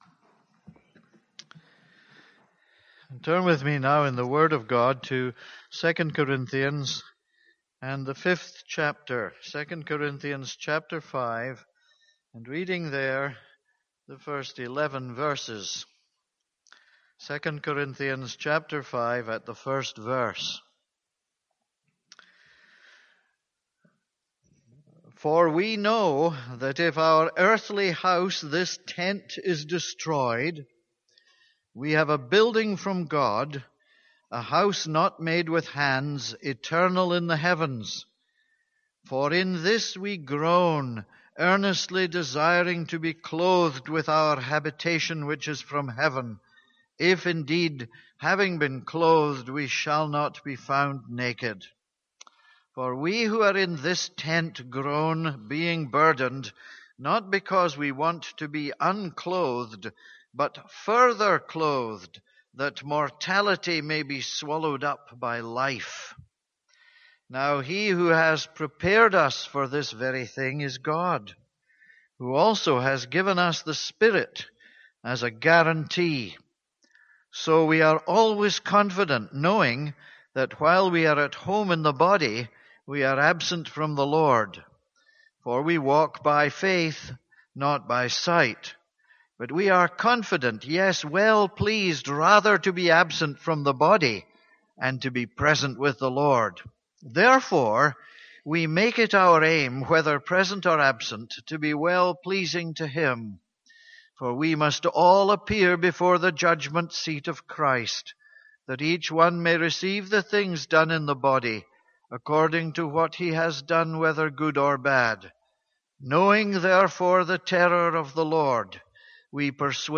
This is a sermon on 2 Corinthians 5:1-11.